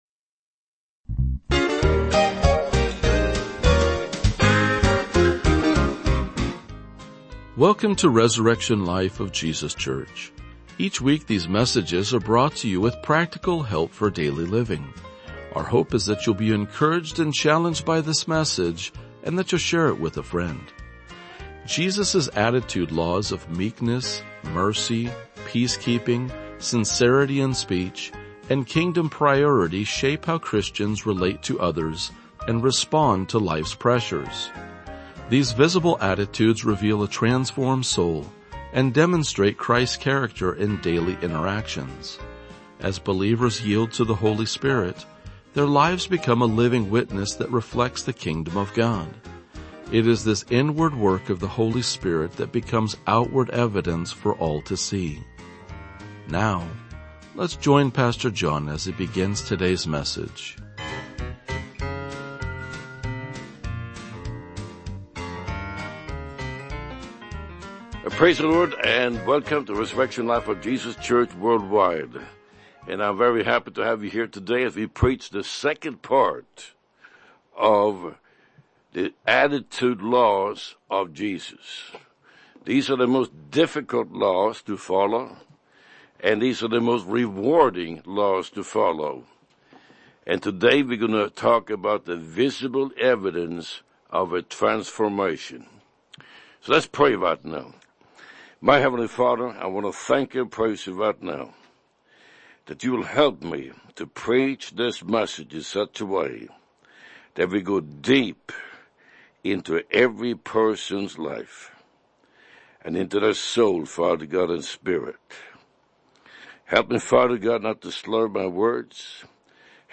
RLJ-2046-Sermon.mp3